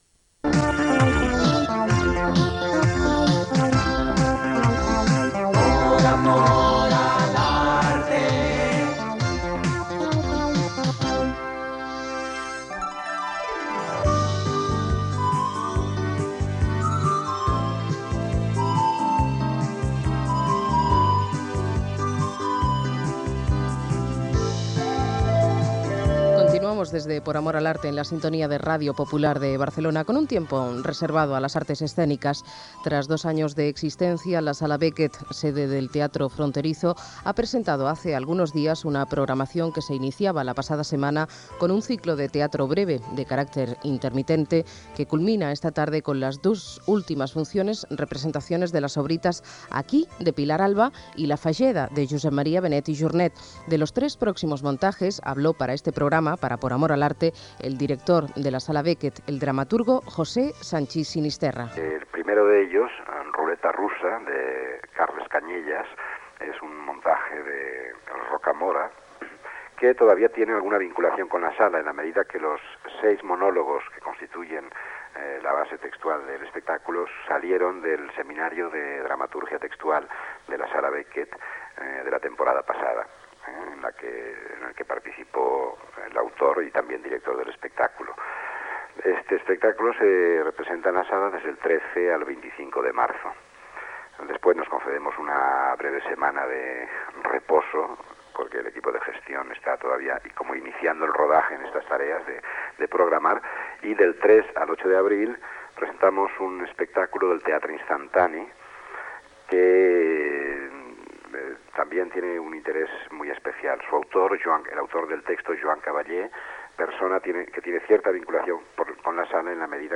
La Sala Beckett de Barcelona fa un cicle de teatre breu, declaracions del seu director José Sanchís Sinisterra